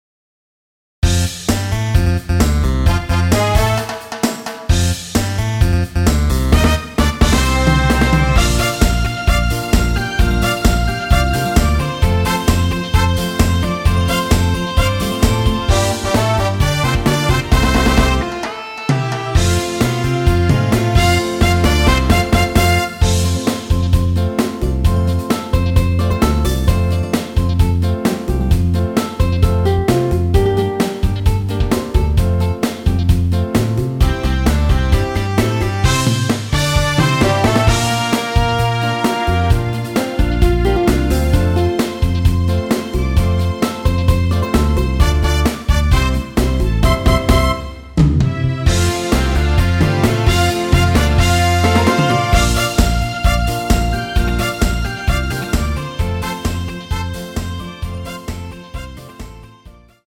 MR입니다.
앞부분30초, 뒷부분30초씩 편집해서 올려 드리고 있습니다.
중간에 음이 끈어지고 다시 나오는 이유는